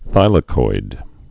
(thīlə-koid)